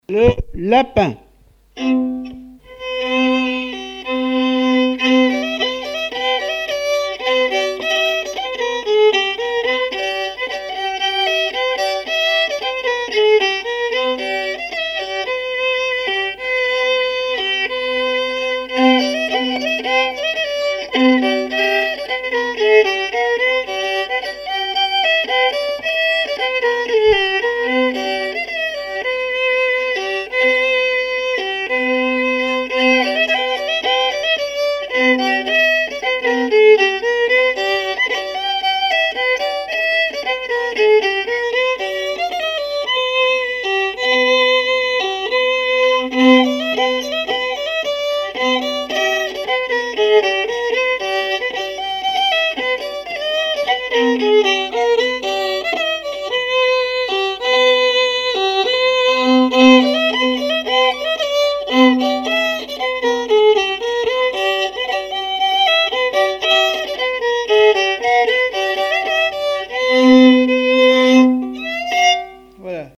Mémoires et Patrimoines vivants - RaddO est une base de données d'archives iconographiques et sonores.
danse : polka lapin
répertoire musical au violon
Pièce musicale inédite